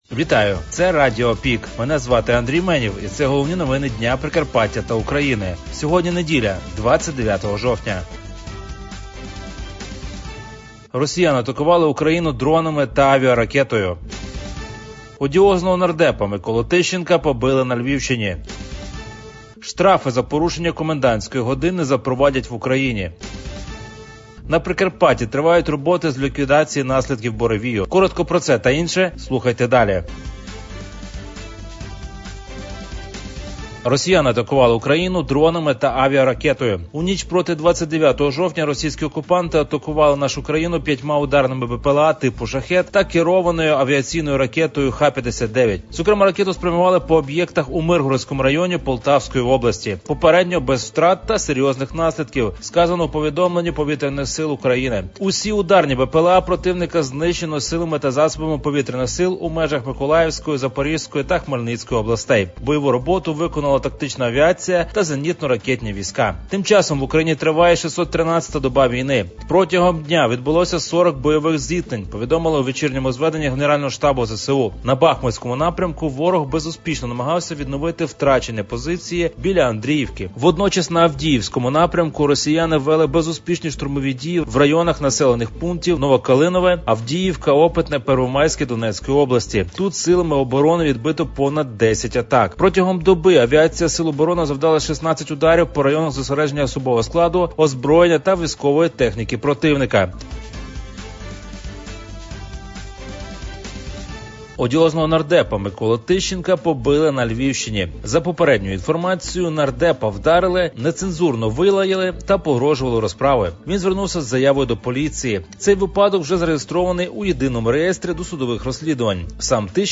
Про актуальне за день у радіоформаті.